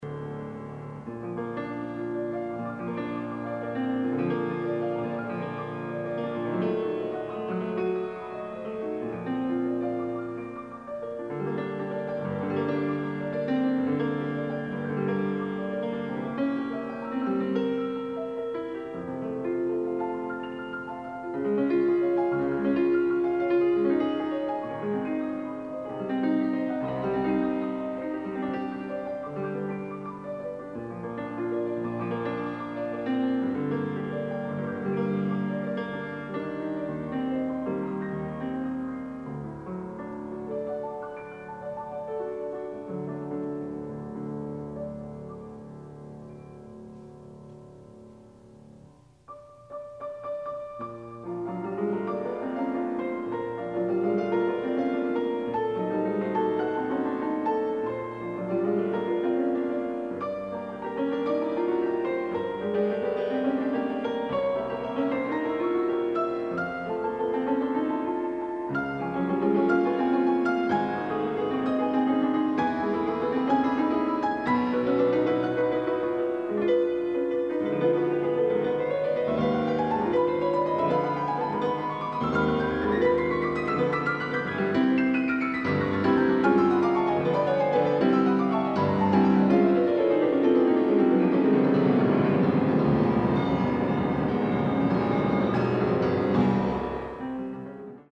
Soloist
Recorded September 11, 1977 in the Ed Landreth Hall, Texas Christian University, Fort Worth, Texas
Sonatas (Piano)
Suites (Piano)
performed music